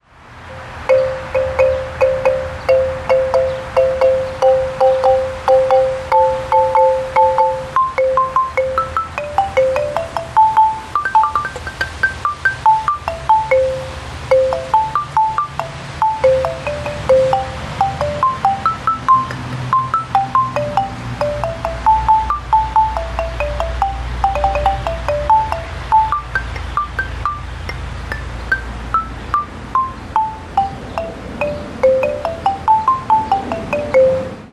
The Akambira, derived from an African xylophone, allows children to experiment with a C major pentatonic scale to make music that is always pleasing to the ear.
• Derived from an African xylophone
• Made of weather-resistant ipé wood keys and sturdy cedar legs
All keys are crafted of dense ipé wood, legs of weather resistant cedar, hardware of stainless/galvanized steel.